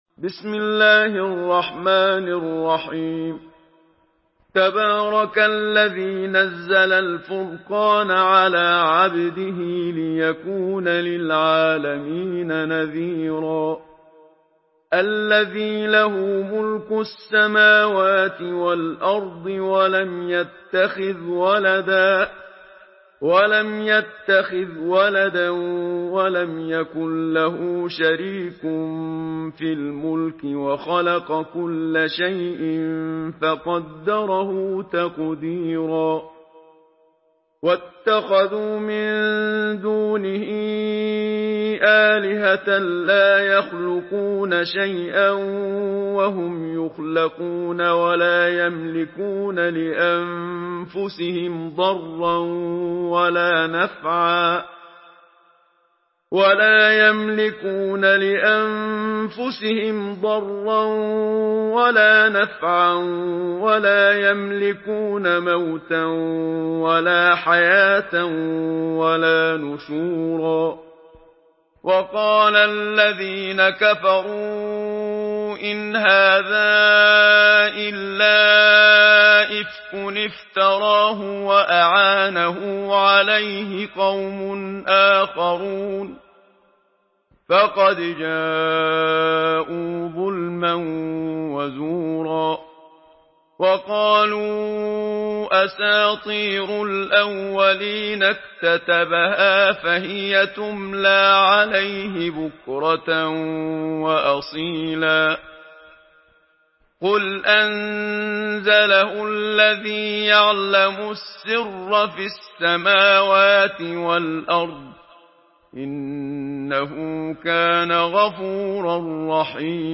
Surah الفرقان MP3 in the Voice of محمد صديق المنشاوي in حفص Narration
مرتل